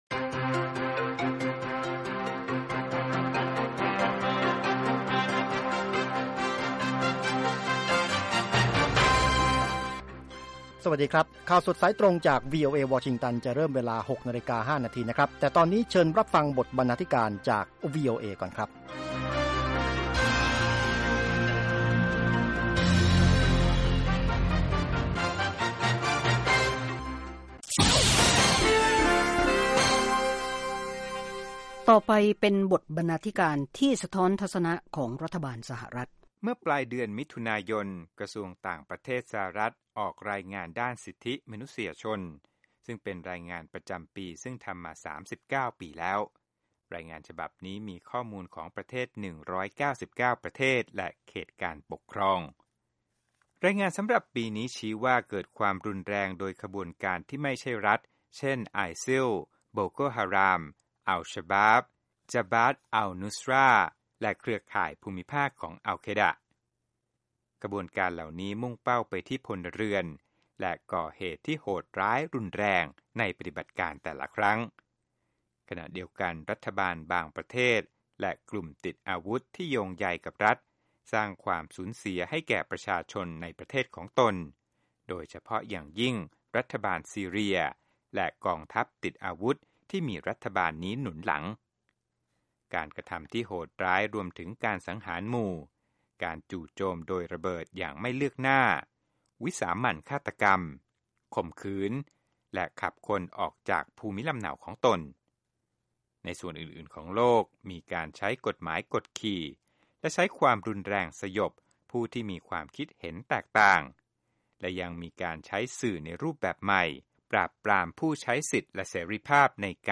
ข่าวสดสายตรงจากวีโอเอ ภาคภาษาไทย 6:00 – 6:30 น วันศุกร์ 17 ก.ค. 2558